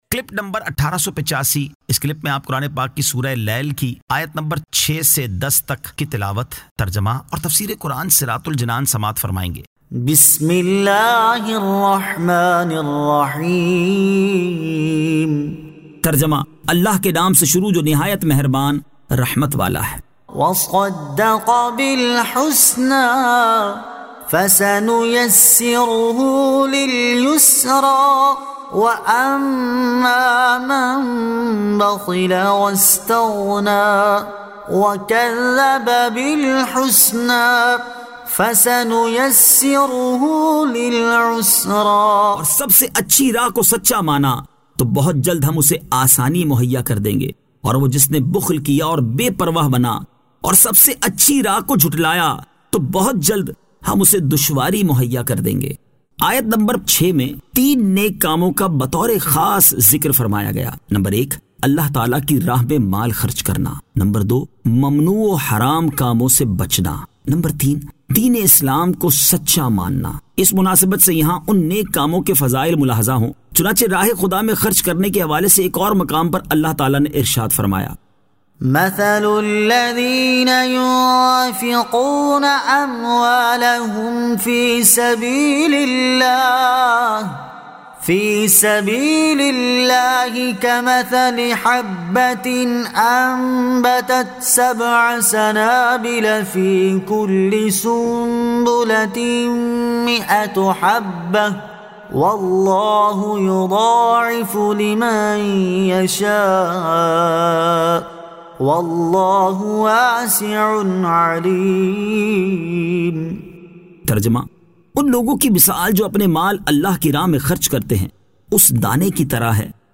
Surah Al-Lail 06 To 10 Tilawat , Tarjama , Tafseer